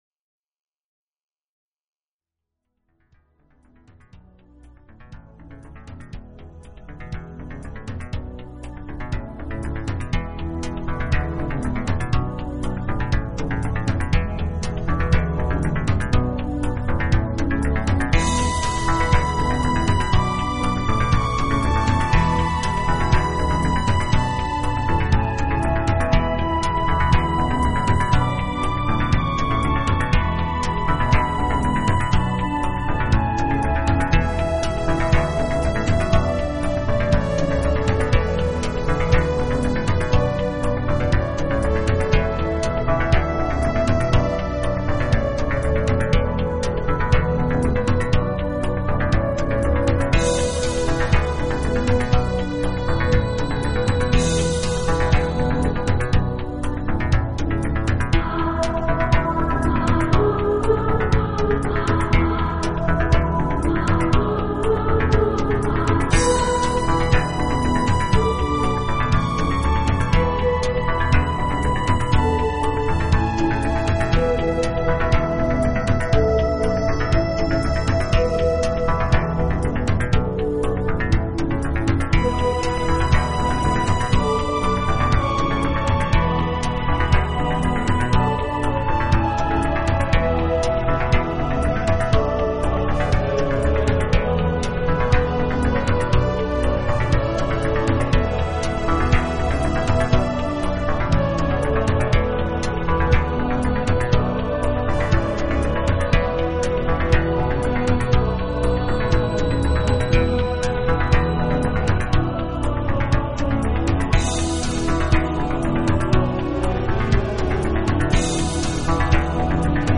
音乐类型: New Age
空灵飘渺的音乐，氛围气氛很浓厚，适合一个人夜里静静聆听。